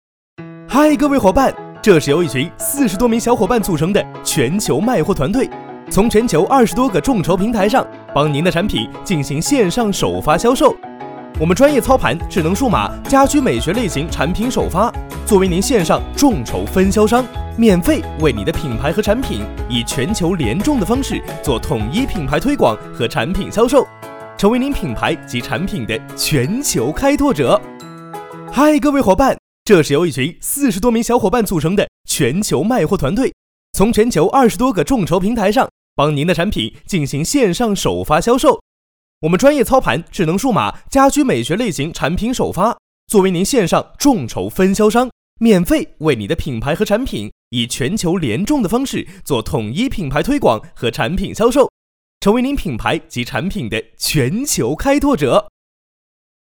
解说：